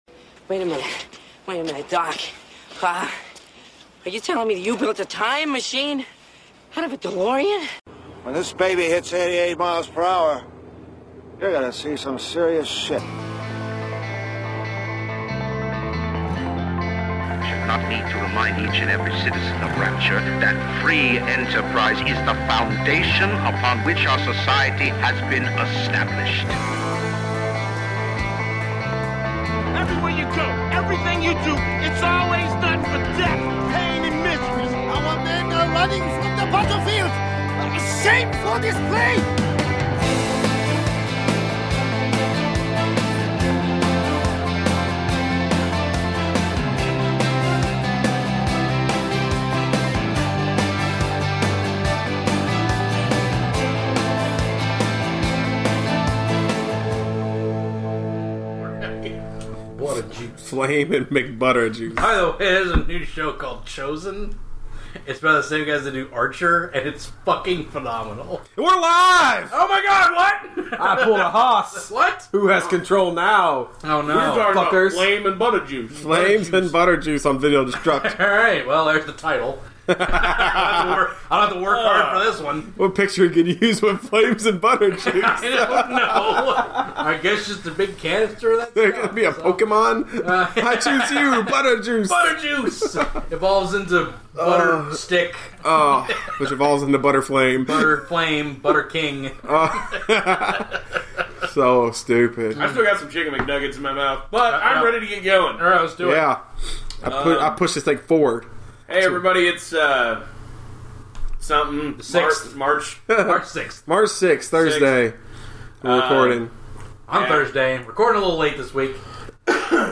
We get REALLY angry with each other over the pronunciation of a word that isn’t actually real.